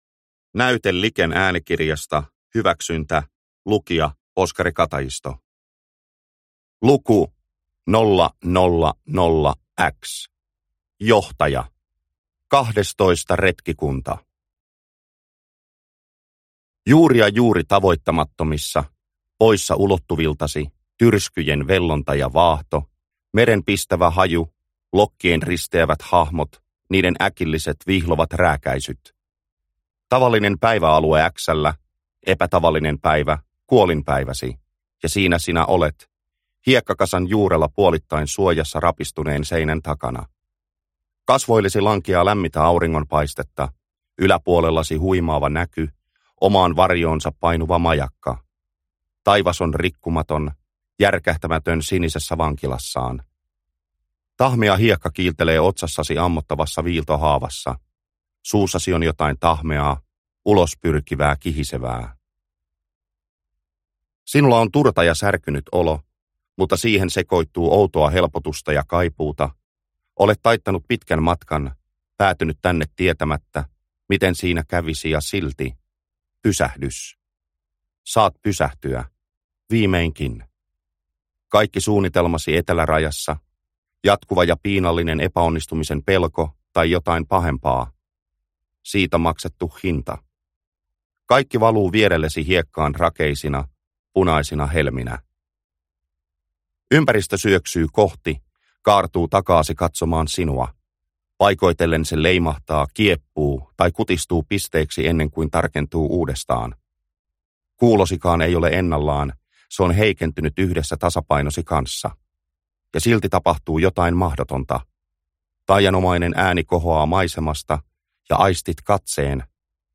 Hyväksyntä – Ljudbok – Laddas ner